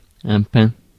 Ääntäminen
Synonyymit pinasse pinace Ääntäminen France: IPA: [pɛ̃] Haettu sana löytyi näillä lähdekielillä: ranska Käännös Konteksti Substantiivit 1. pine tree kasvitiede 2. pine Suku: m .